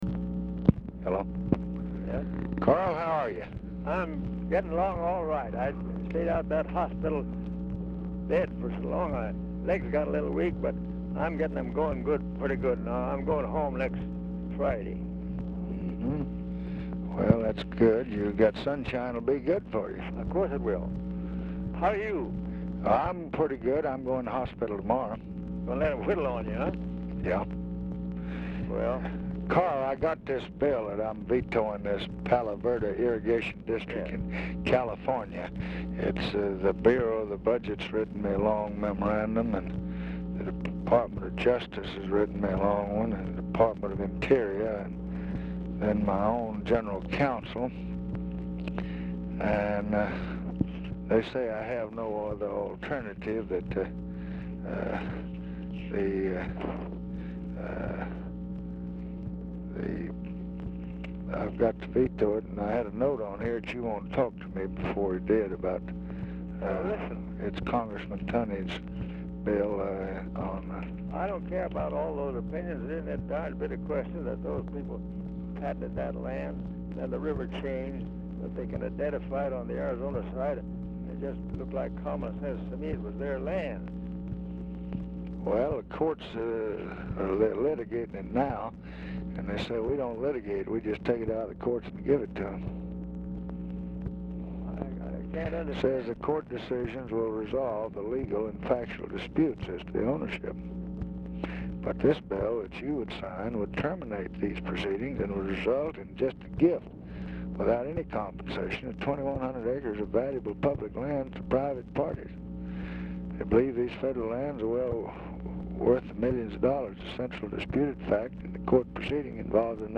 Telephone conversation # 11040, sound recording, LBJ and CARL HAYDEN, 11/14/1966, 3:29PM
Format Dictation belt
Location Of Speaker 1 LBJ Ranch, near Stonewall, Texas